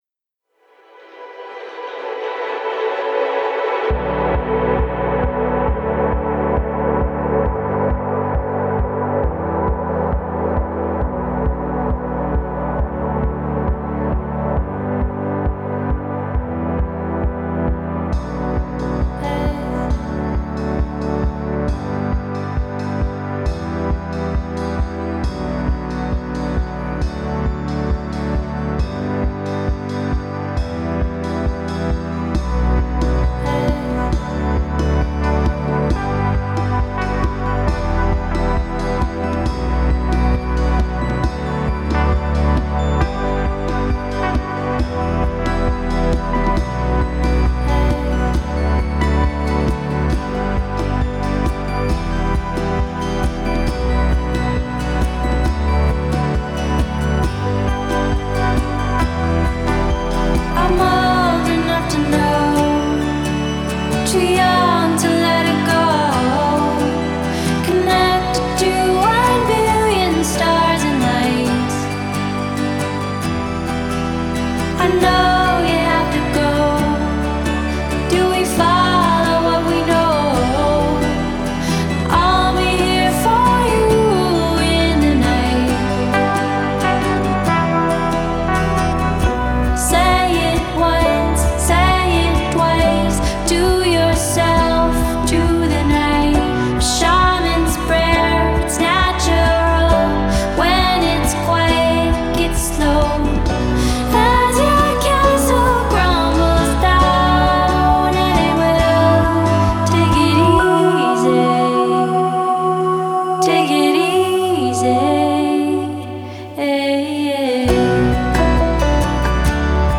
Genre: Indie Pop, Pop Folk, Singer-Songwriter